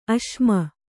♪ aśma